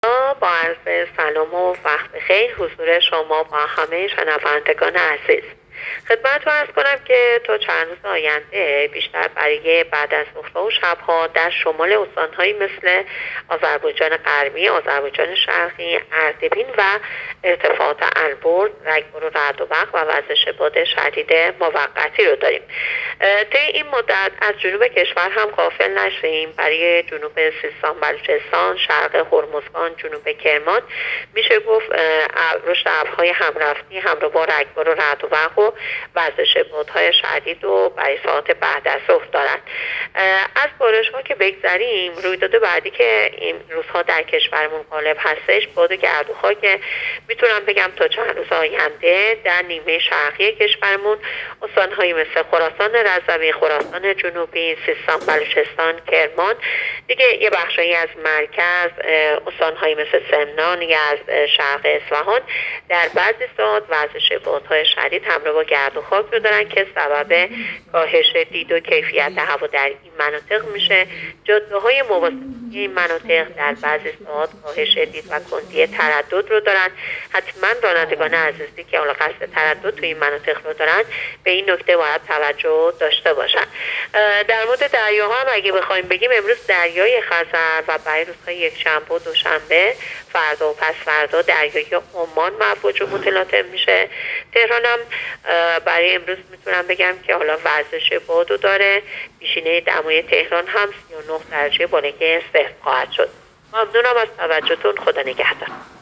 گزارش رادیو اینترنتی پایگاه‌ خبری از آخرین وضعیت آب‌وهوای ۴ مرداد؛